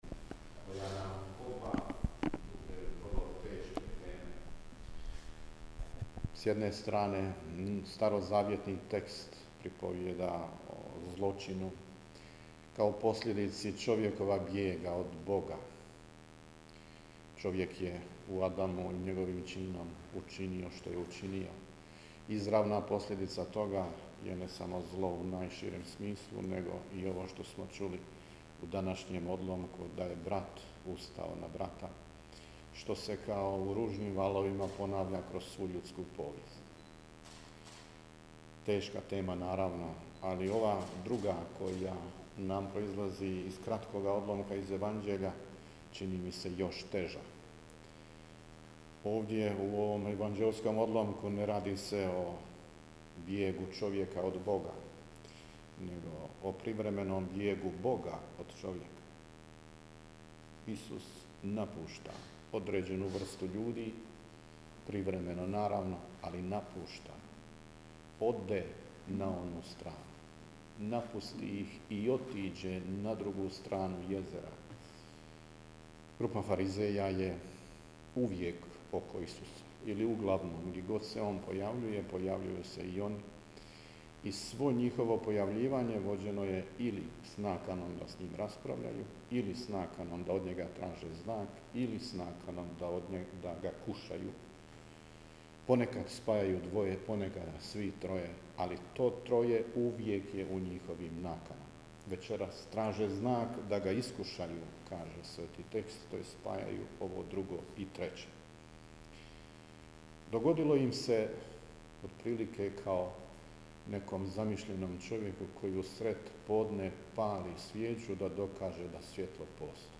Susret je započeo Misnim slavljem koje je u kapeli Centra predvodio nadbiskup Vukšić u zajedništvu s biskupom Rogićem i uz koncelebraciju svih povjerenika.